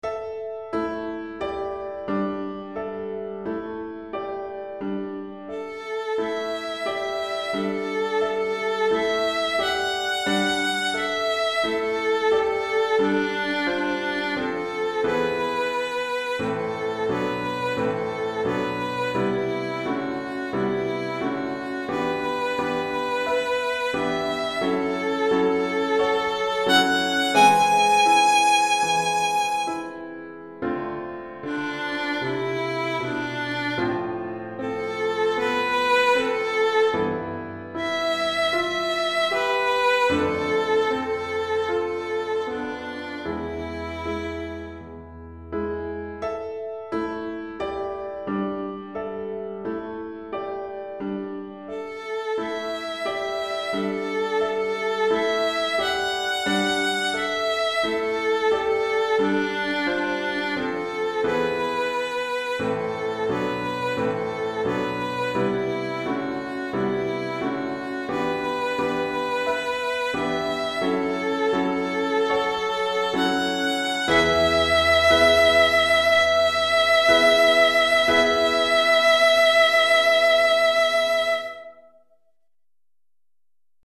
pour violon et piano DEGRE CYCLE 1